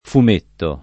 [ fum % tto ]